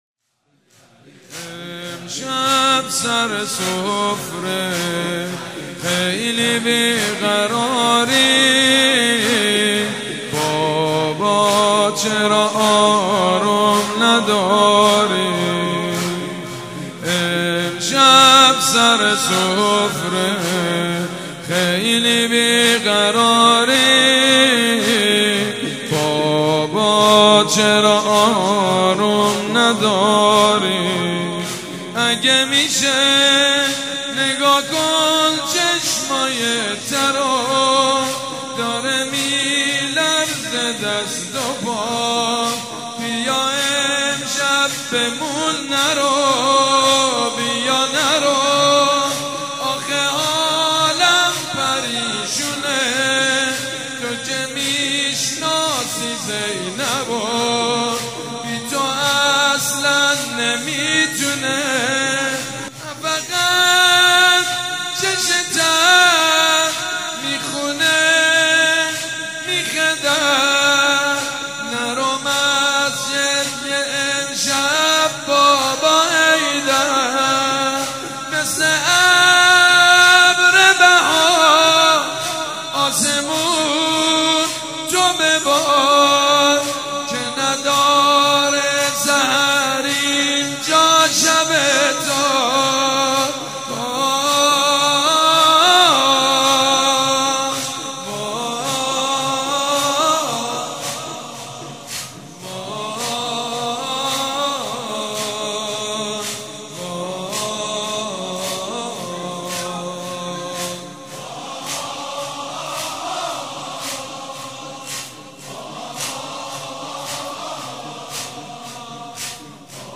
صوت/ مداحی بنی‌فاطمه به‌مناسبت لیالی قدر
مداحی سید مجید بنی‌فاطمه مداح اهل بیت عصمت و طهارت به مناسبت لیالی قدر را بشنوید.